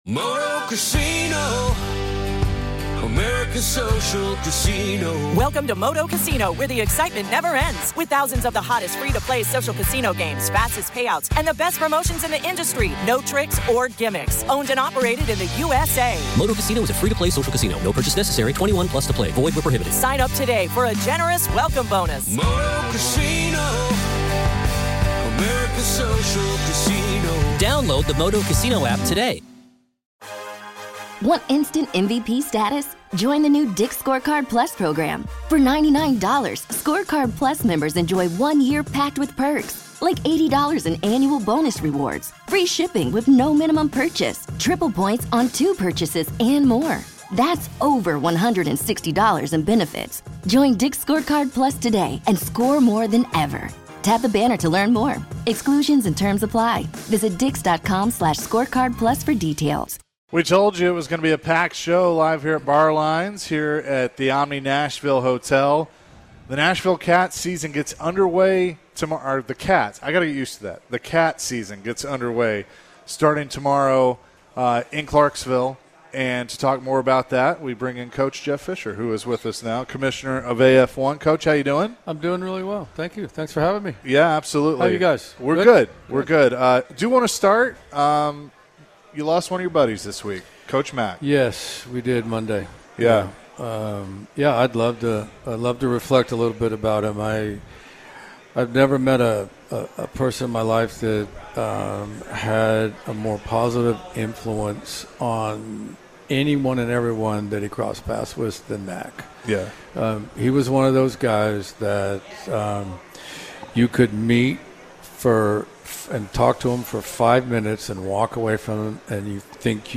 AF1 Commissioner Jeff Fisher joined the show and shared his thoughts on the upcoming season for the Kats and the Titans draft. Jeff also took time to remember friend and coach Dave McGinnis.